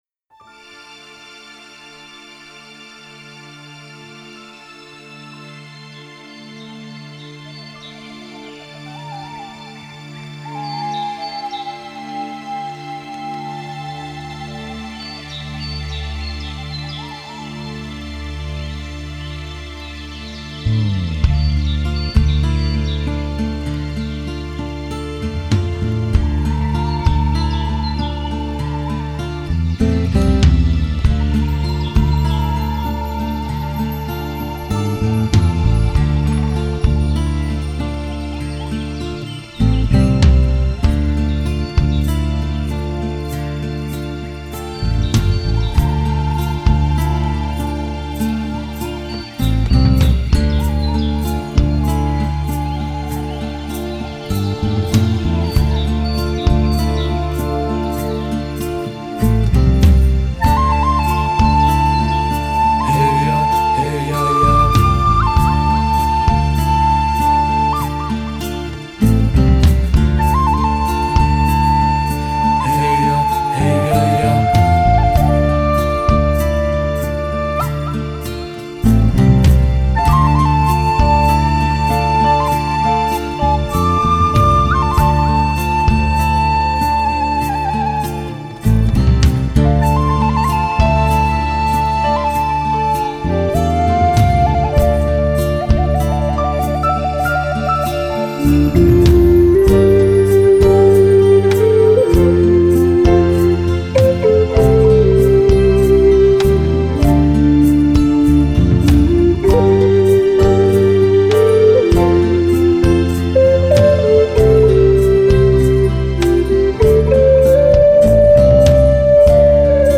Genre: Native American.